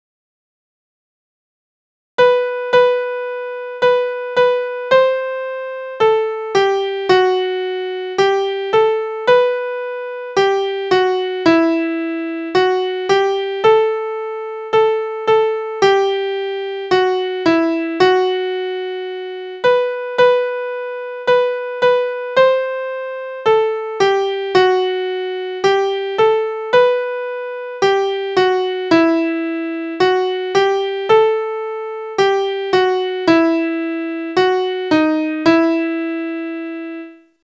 De melodie is heel eenvoudig (